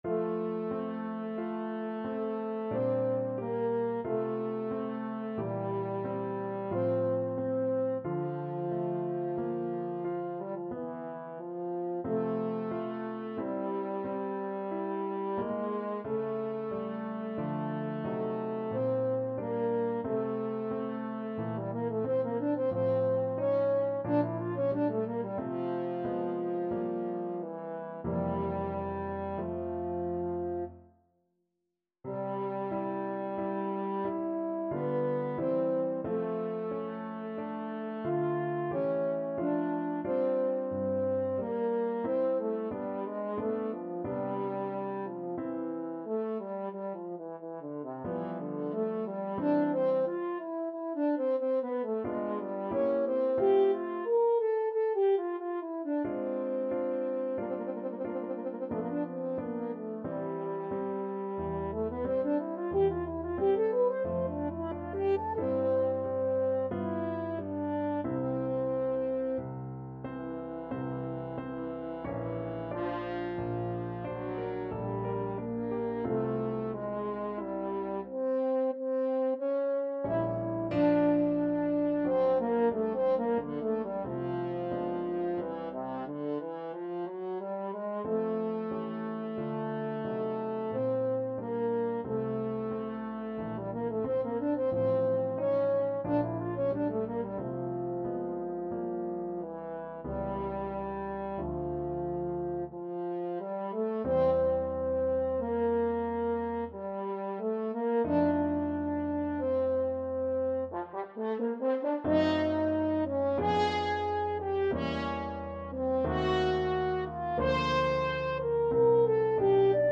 French Horn
F major (Sounding Pitch) C major (French Horn in F) (View more F major Music for French Horn )
3/4 (View more 3/4 Music)
Adagio =45
Classical (View more Classical French Horn Music)